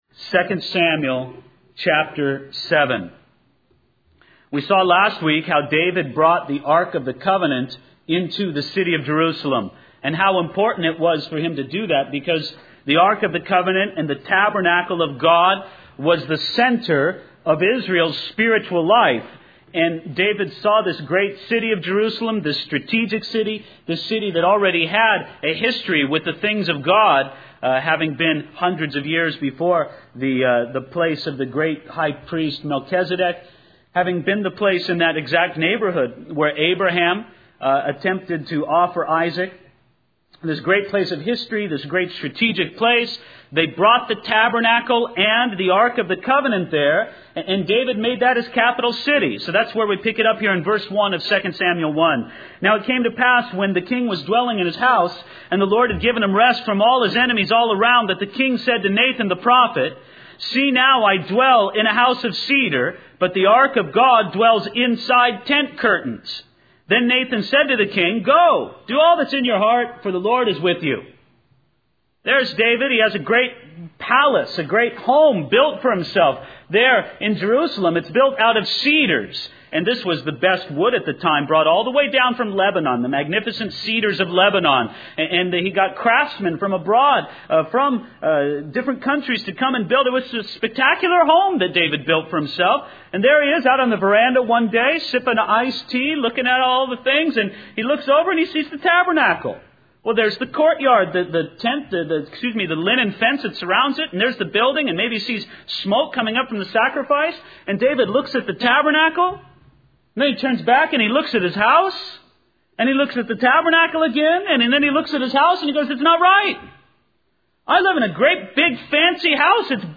In this sermon, the speaker emphasizes the importance of believers going above and beyond in their relationship with God.